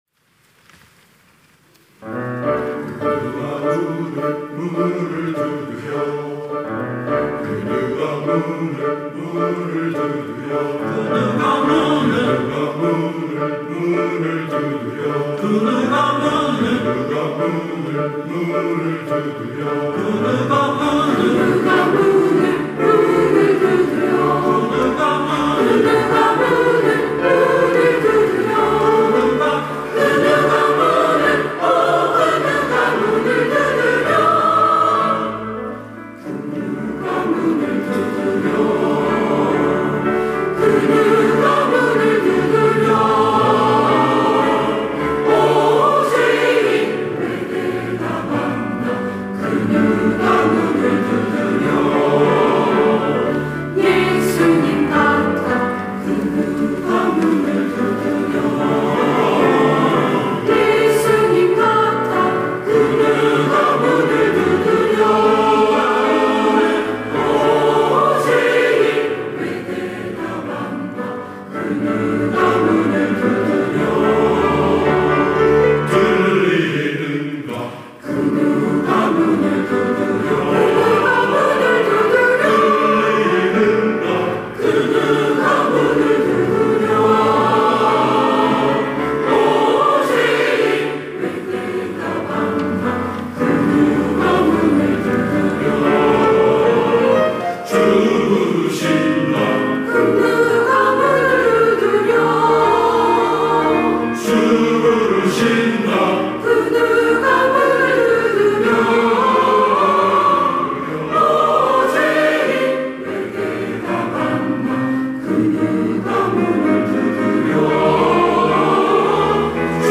시온(주일1부) - 그 누가 문을 두드려
찬양대